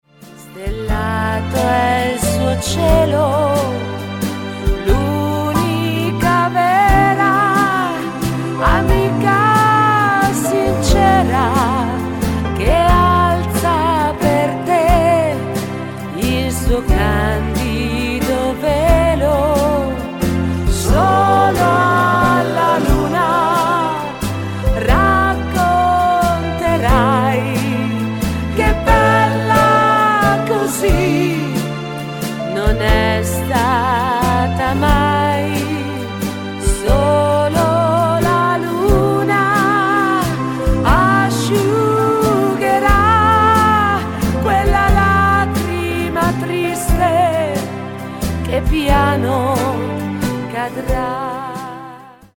VALZER LENTO  (4.29)